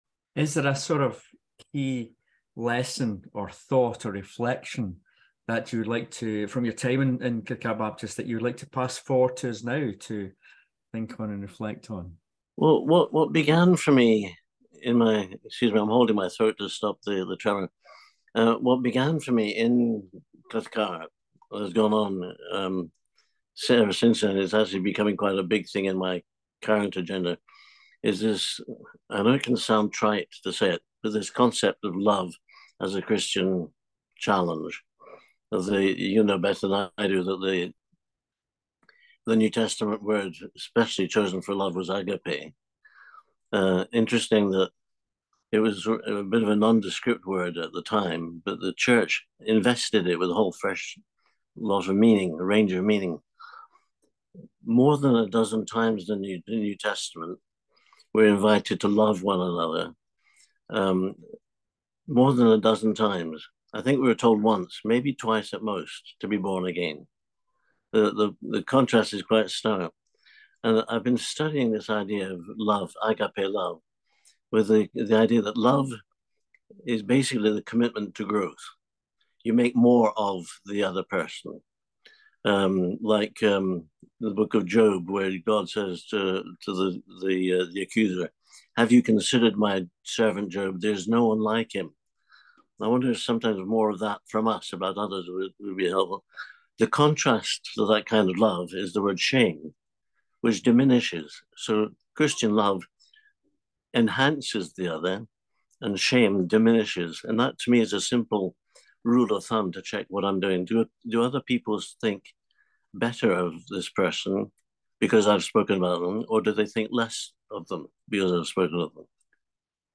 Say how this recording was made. recorded in 2023 over zoom.